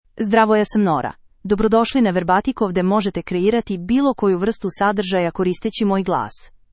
Nora — Female Serbian AI voice
Nora is a female AI voice for Serbian (Serbia).
Voice sample
Listen to Nora's female Serbian voice.
Female
Nora delivers clear pronunciation with authentic Serbia Serbian intonation, making your content sound professionally produced.